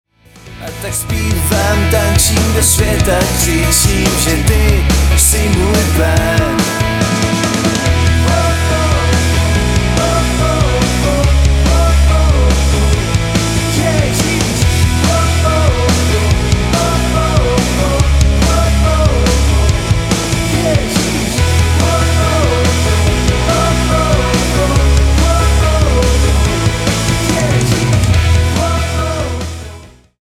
Sborový zpěv: